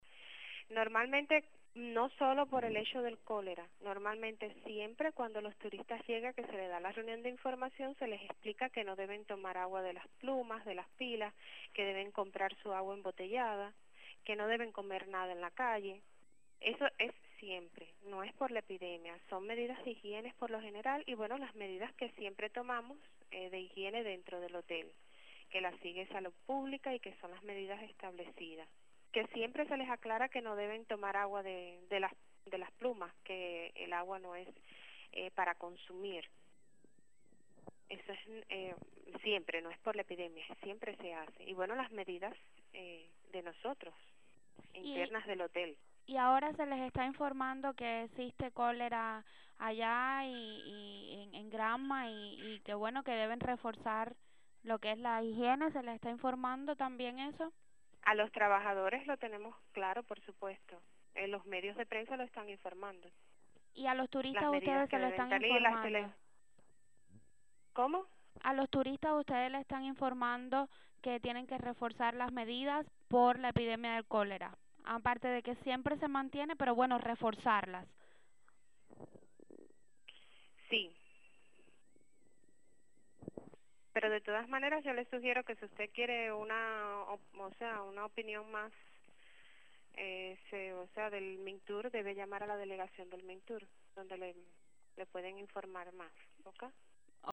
Declaraciones desde el Hotel Encanto San Basilio